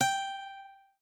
lute_g.ogg